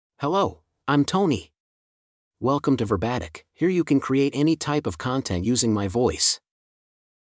MaleEnglish (United States)
TonyMale English AI voice
Tony is a male AI voice for English (United States).
Voice sample
Tony delivers clear pronunciation with authentic United States English intonation, making your content sound professionally produced.